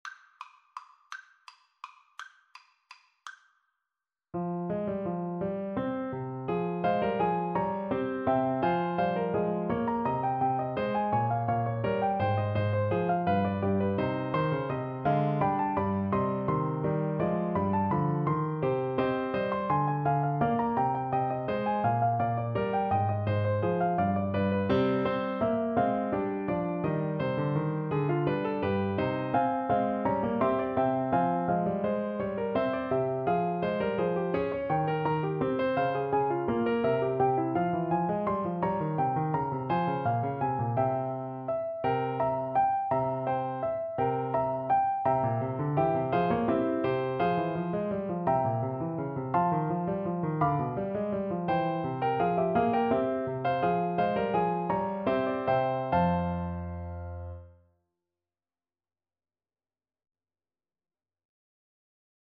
Play (or use space bar on your keyboard) Pause Music Playalong - Piano Accompaniment Playalong Band Accompaniment not yet available reset tempo print settings full screen
3/8 (View more 3/8 Music)
F minor (Sounding Pitch) (View more F minor Music for Trombone )